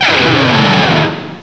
cry_not_fraxure.aif